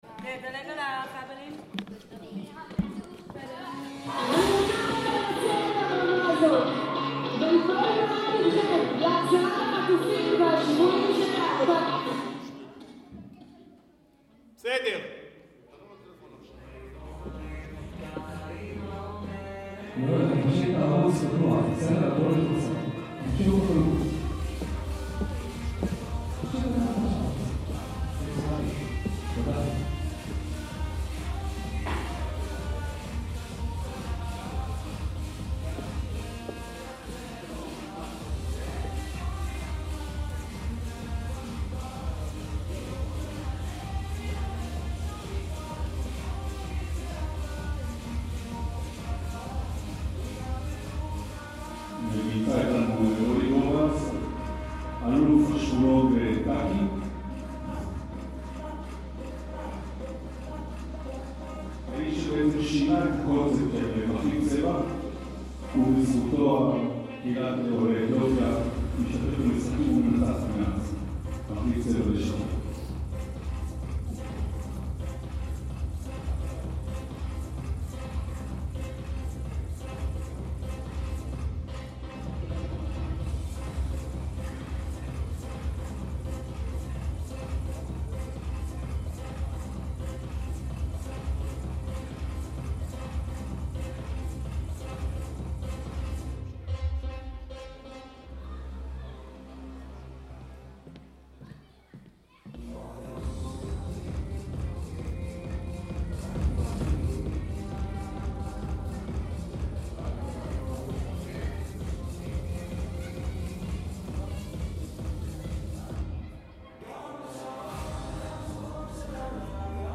קובץ שמע 1 של פרוטוקול ישיבות מועצה מס' 9